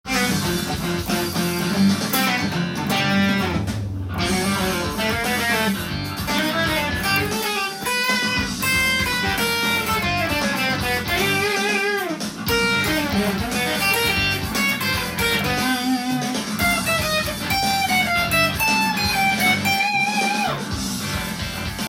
クロマチックスケール
アドリブで弾いてみました
緊張感　満載で危険な雰囲気がするソロになりました。
フュージョン感が出ますね♪